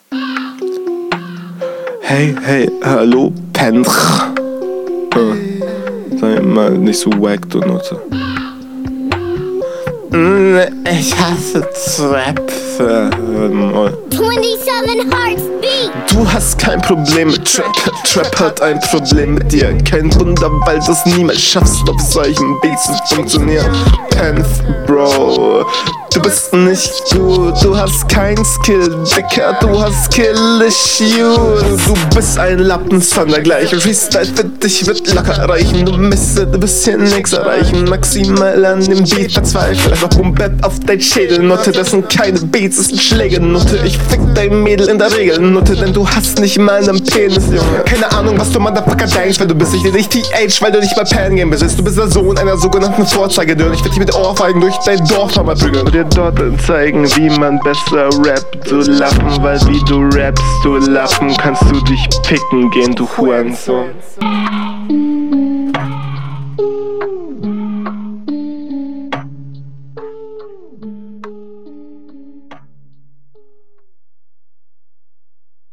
attidude super text auch ausreichend schön von oben herab passend zur attidude flow ganz ok …